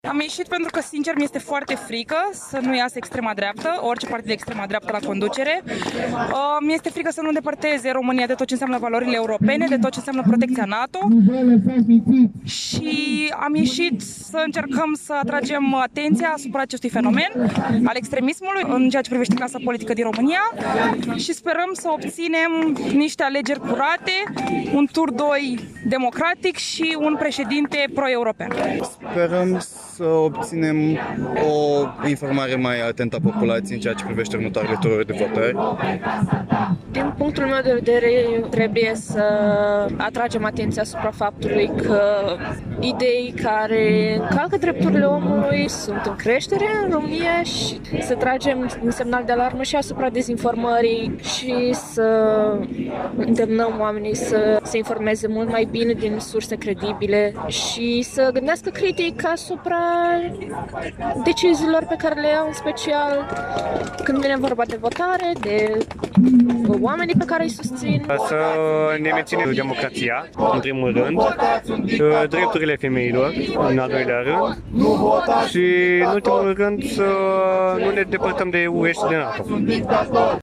Aproximativ 200 de persoane protestează, în Piața Uniri din Iași, pentru a patra seară consecutiv, față de Călin Georgescu, candidatul independent ajuns în turul al doilea al alegerilor prezidențiale.
28-nov-ora-21-vox-studenti.mp3